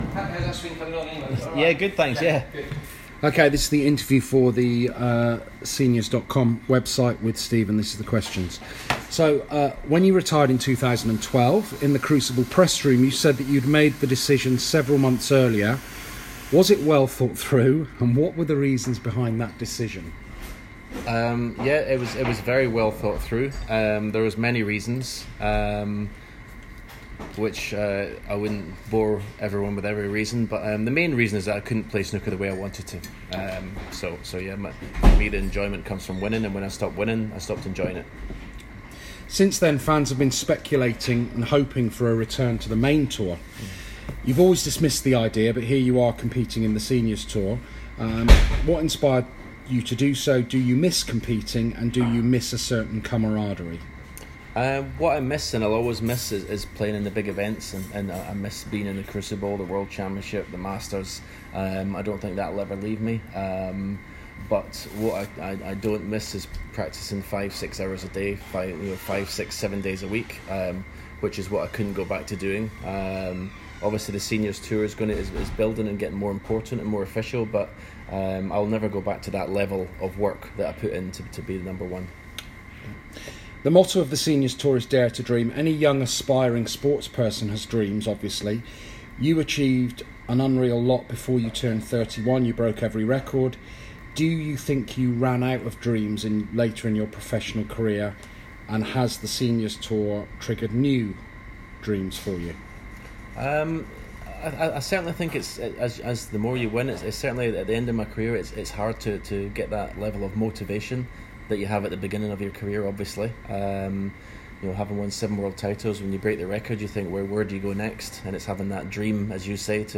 An exclusive interview with Stephen Hendry